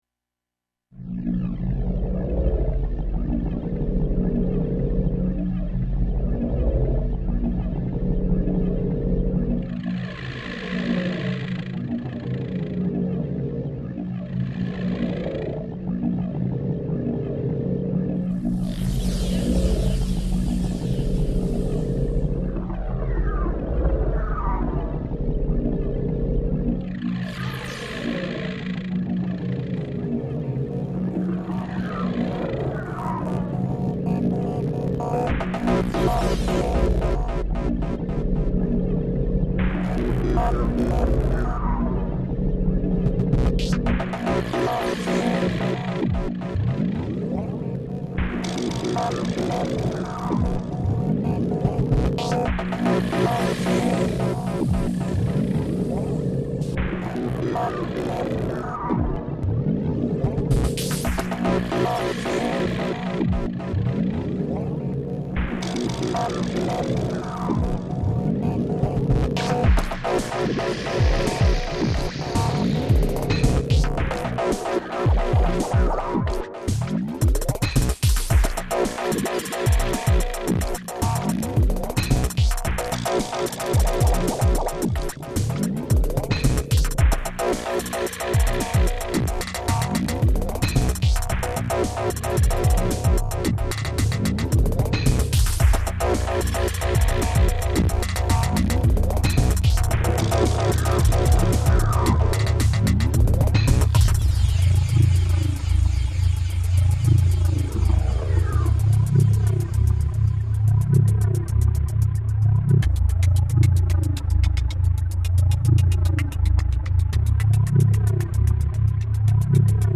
Influences: Prodigy, other good techno, other shitty techno